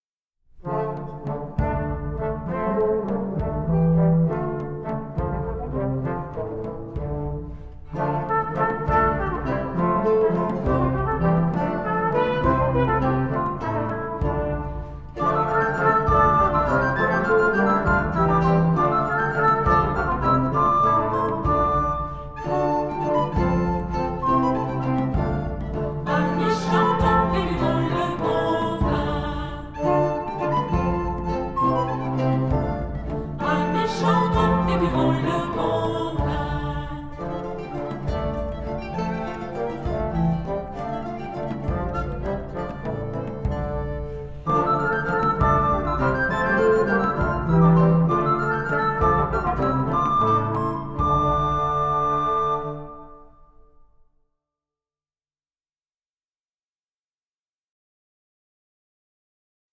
Musique de scène
variation tourdion
la musique originale pour chœur et orchestre de chambre